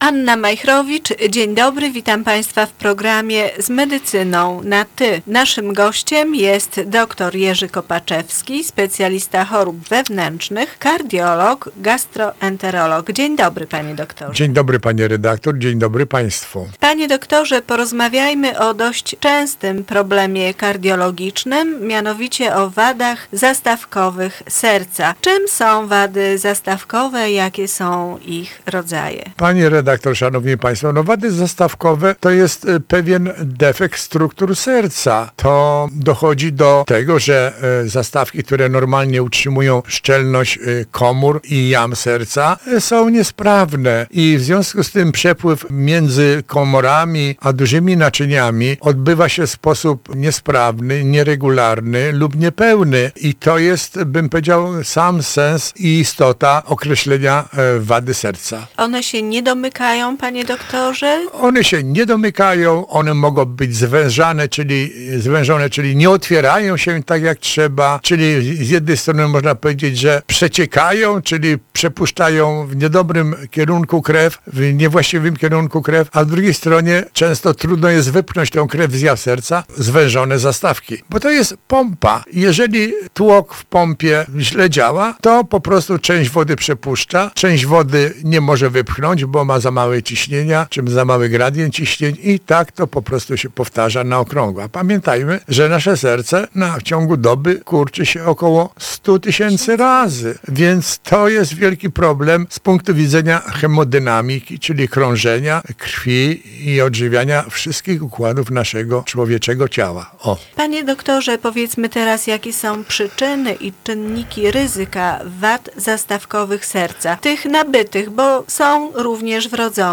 specjalista chorób wewnętrznych, kardiolog, gastroenterolog, gość programu